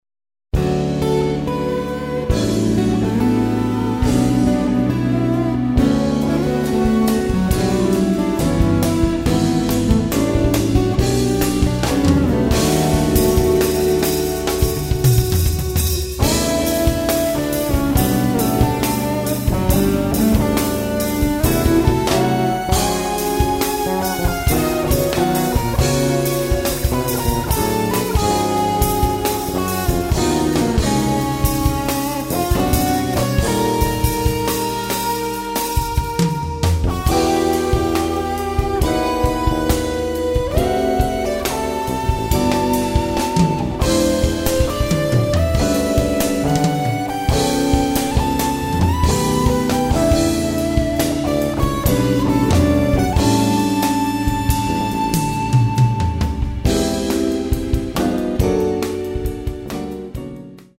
electric guitars & MIDI programming
drums
electric bass
piano
tenor saxophone